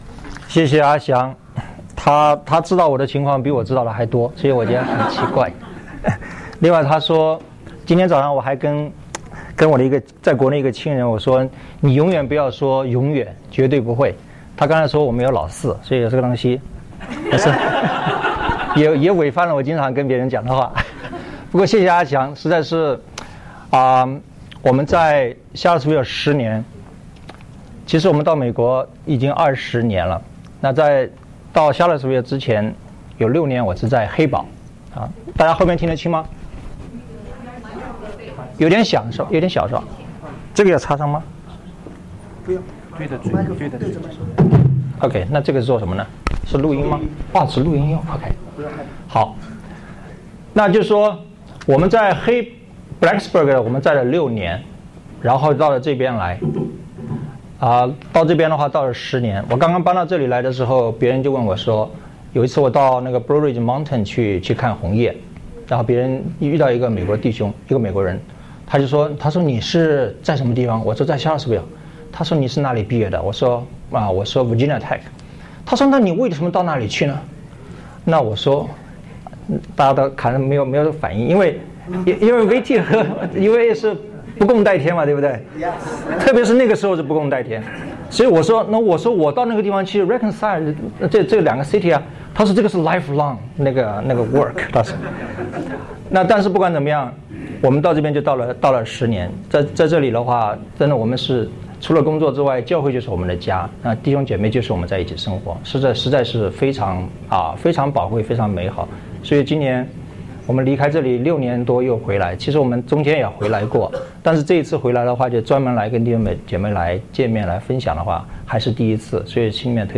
中秋佈道會